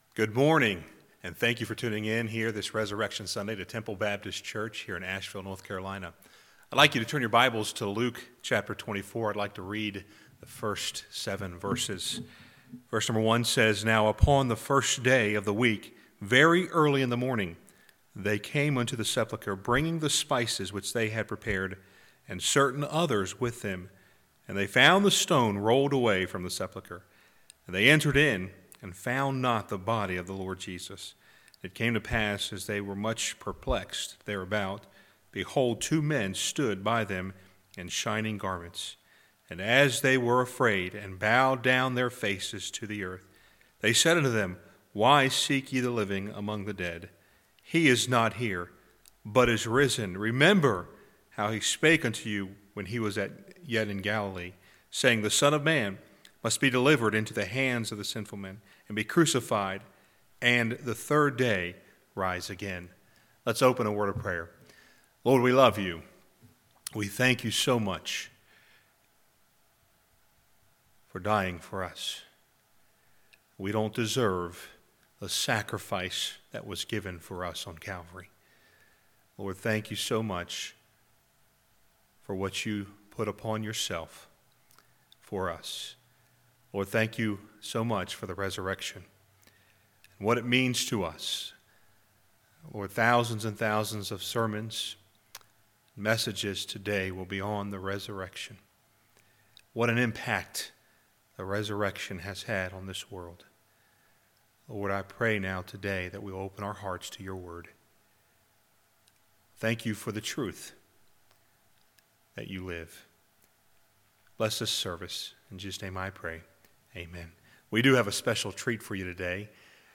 Guest Speaker , Sunday Morning